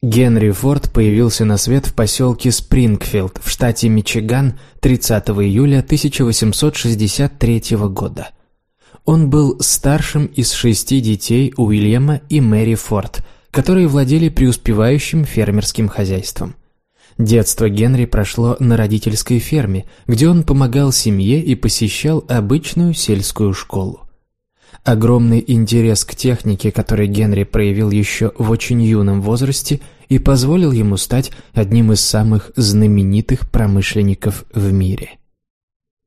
Аудиокнига Генри Форд. Секреты успеха | Библиотека аудиокниг